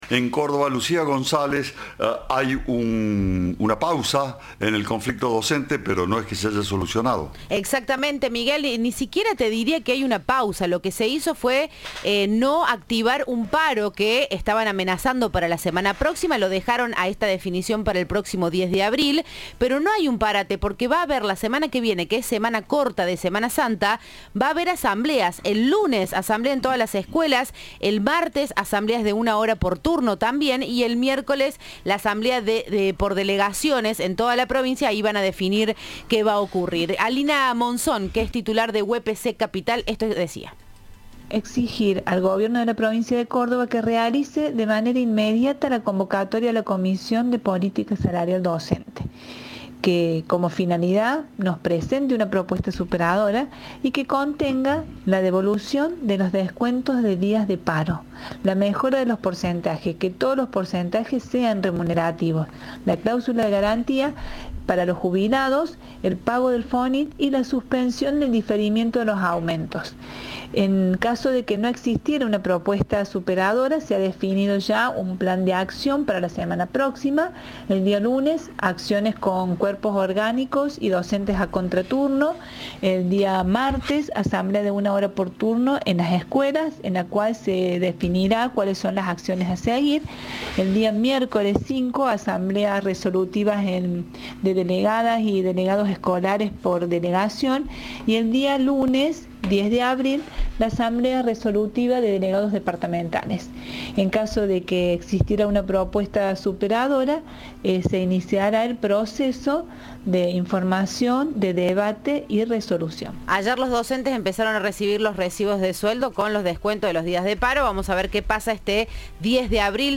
Informe